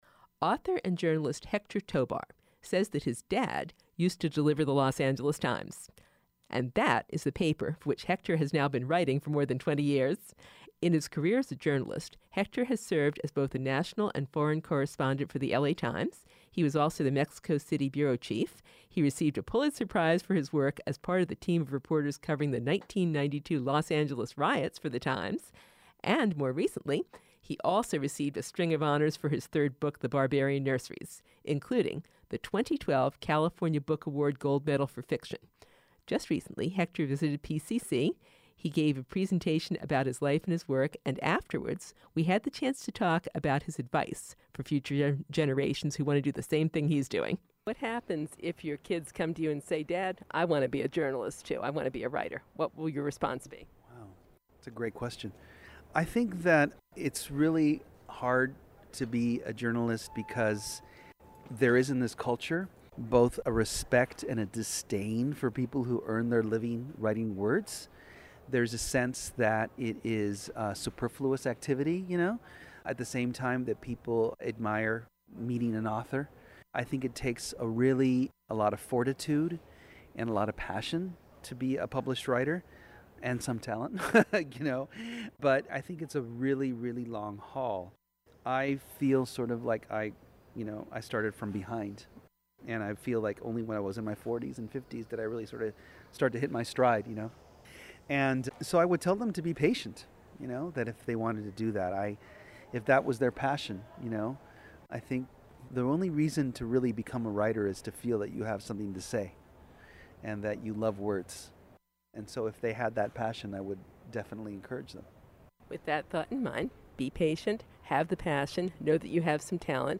Hector Tobar Interview, Part Three